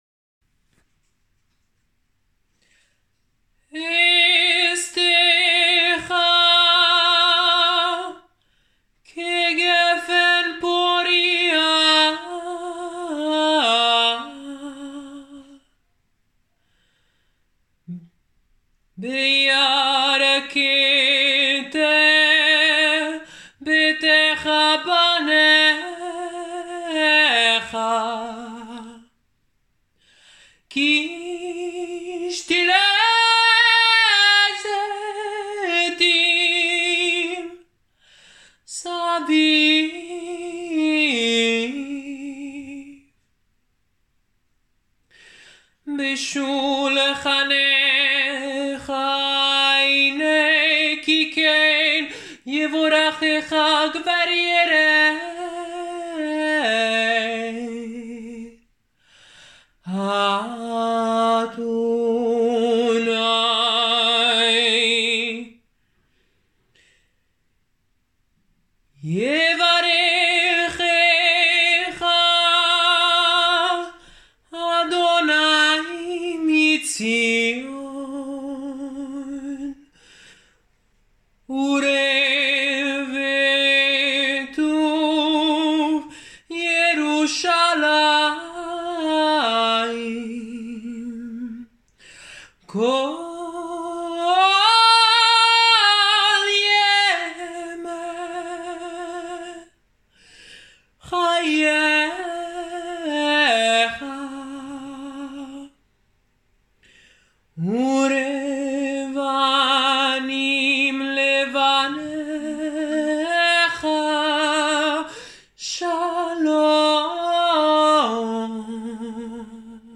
Vi piacerà ora ascoltare il canto del salmo 128, uno dei salmi dei gradini, all’insegna della donna, moglie e madre, Eshtekhà